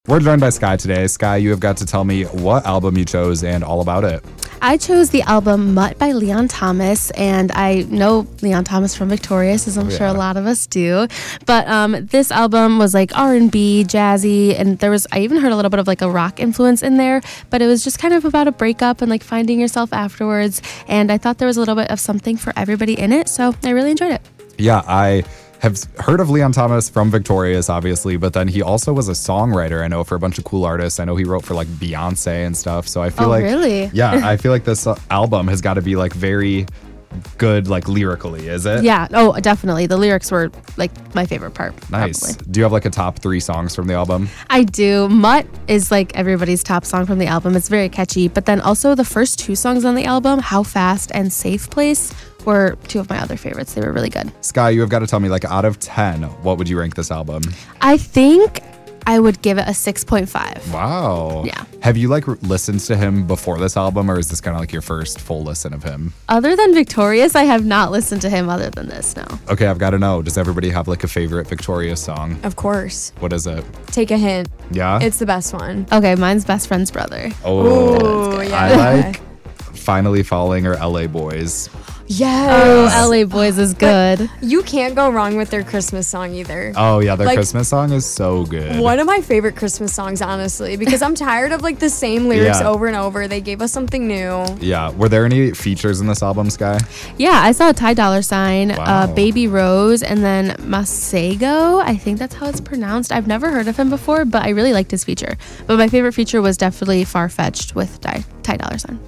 It’s catchy, upbeat, and might even be relatable to some.